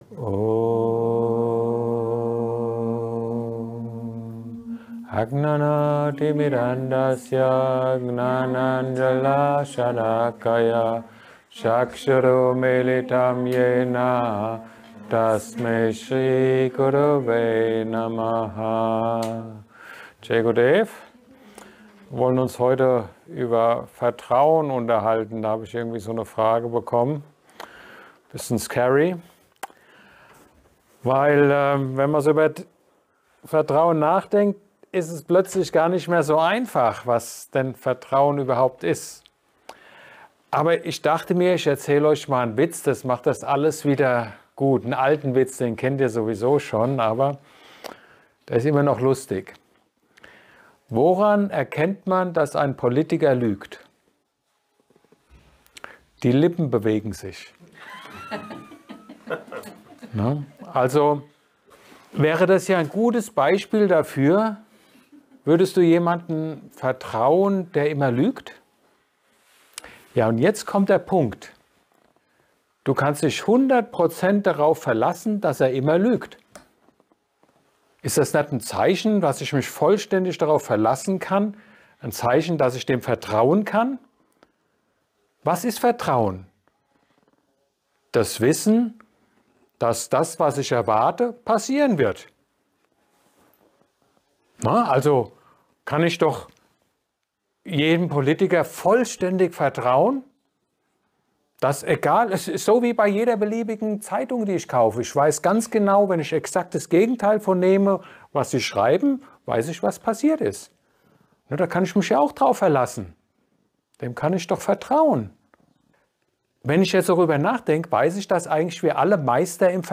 Satsang